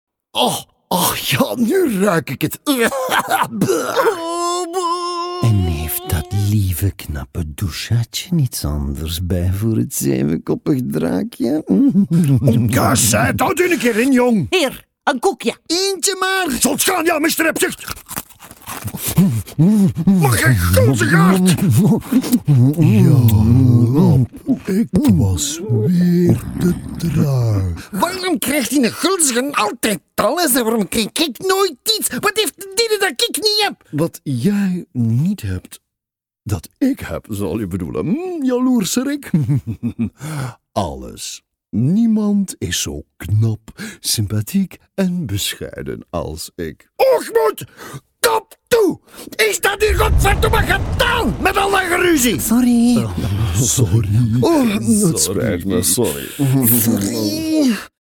Distinctive, Mature, Warm